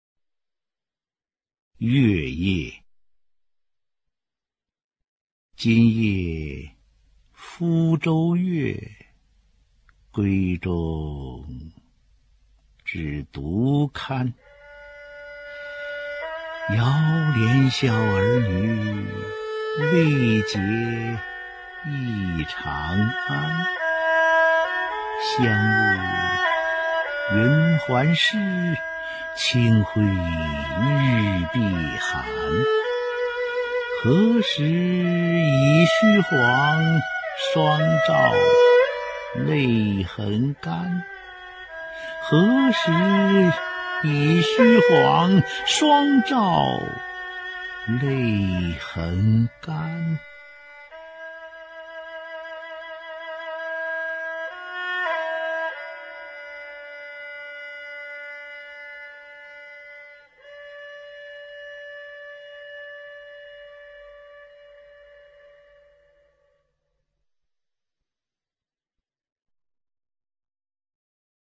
[隋唐诗词诵读]杜甫-月夜（男） 唐诗朗诵